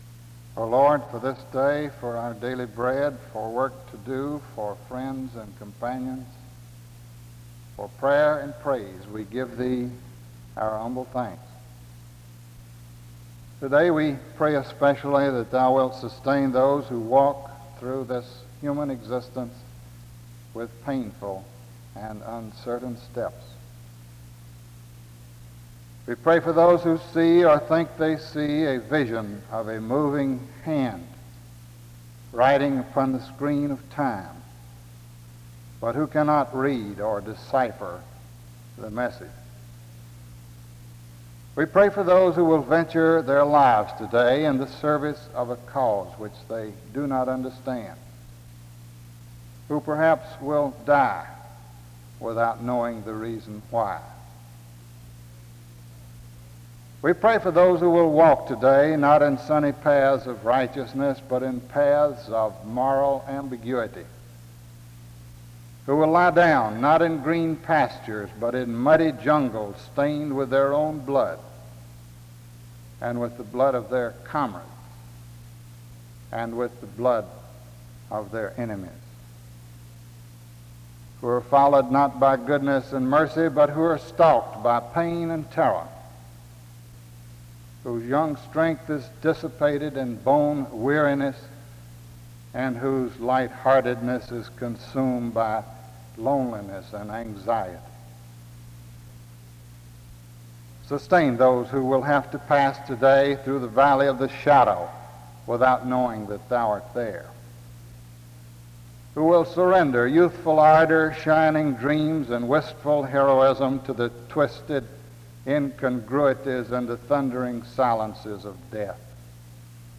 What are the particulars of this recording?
SEBTS Chapel and Special Event Recordings SEBTS Chapel and Special Event Recordings